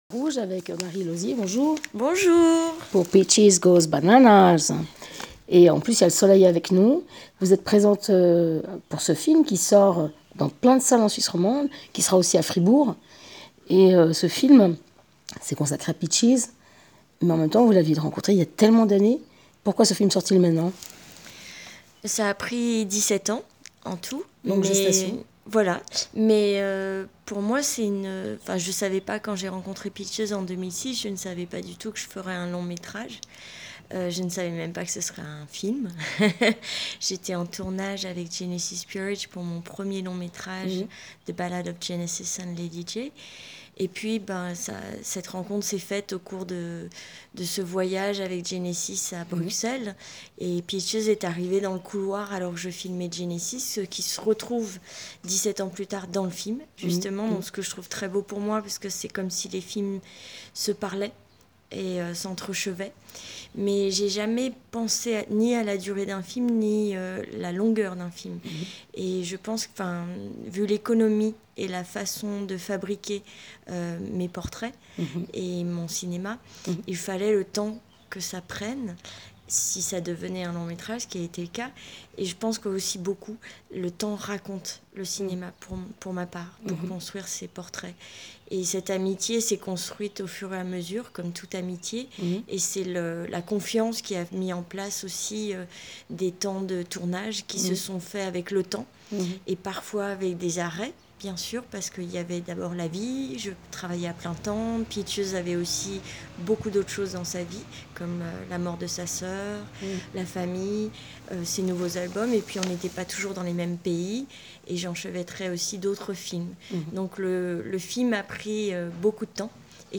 Peaches Goes Bananas : le corps comme outil de militantisme et comme œuvre d’art. Rencontre